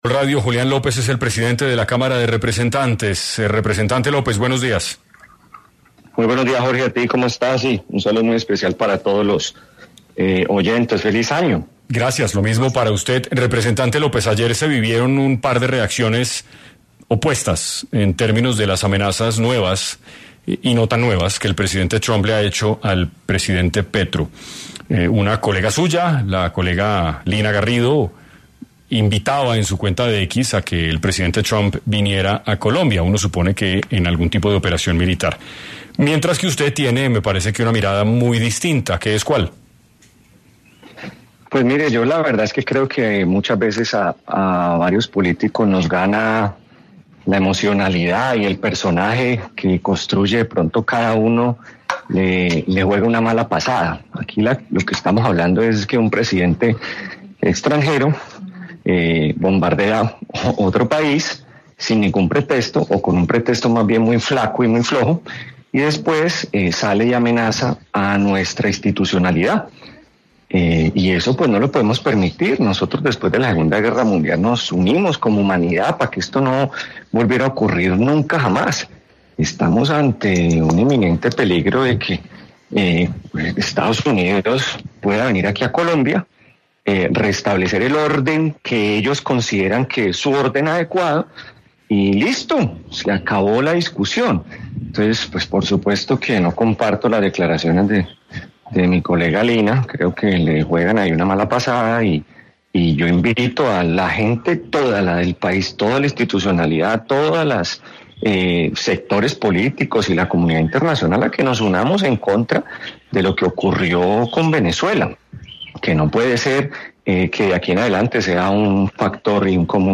Julián López habló en 6AM sobre las declaraciones del presidente de los Estados Unidos sobre Colombia.